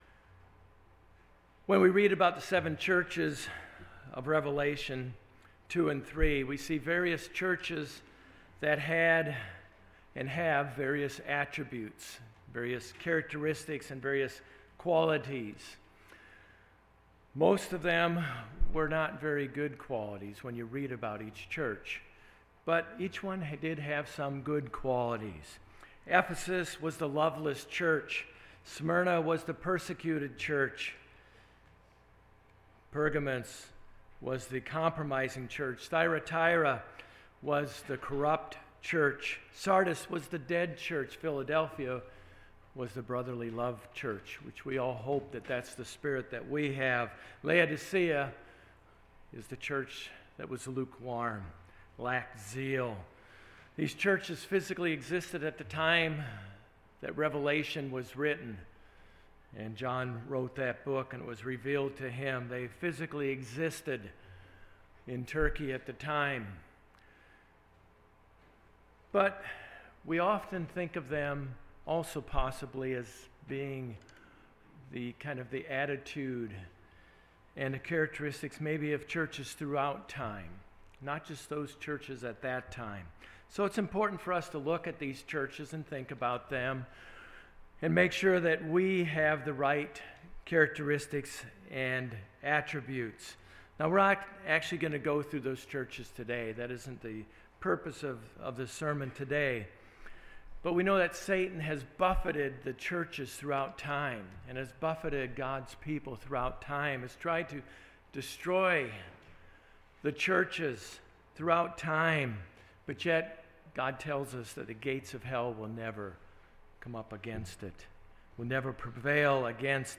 Given in Jacksonville, FL